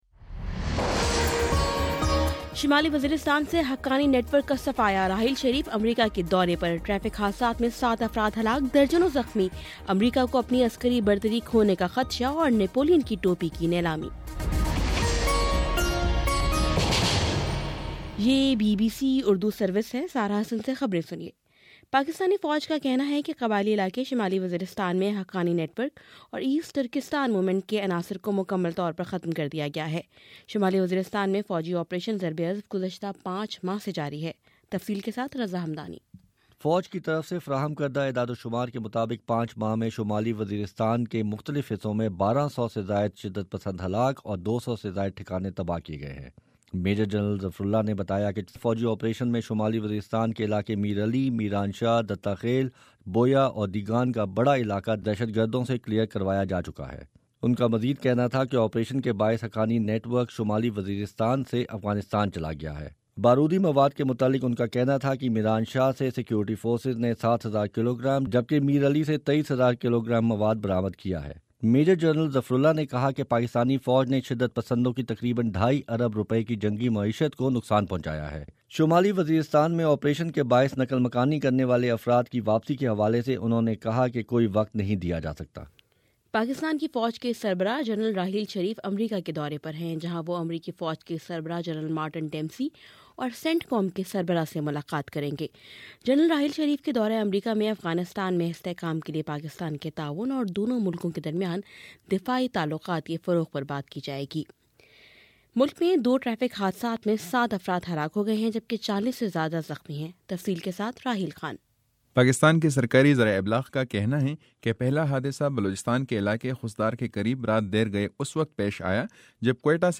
دس منٹ کا نیوز بُلیٹن روزانہ پاکستانی وقت کے مطابق صبح 9 بجے، شام 6 بجے اور پھر 7 بجے۔